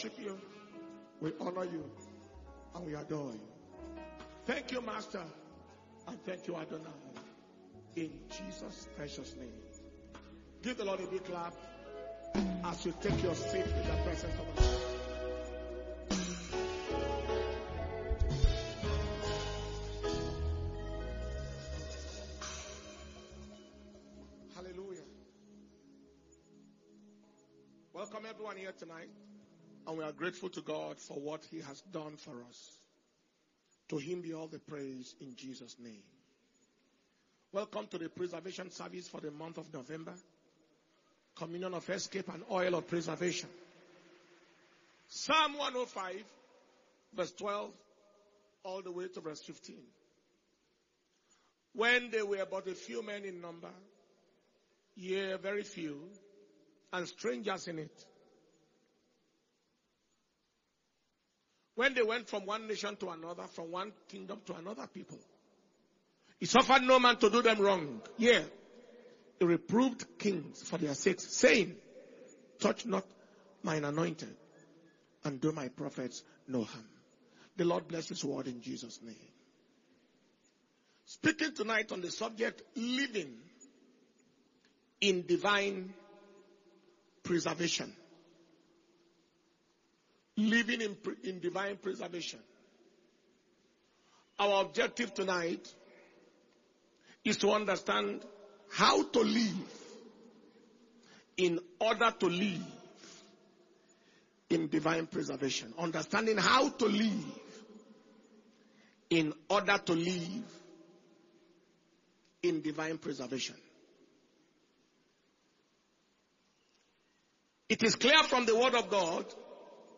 Preservation & Power Communion Service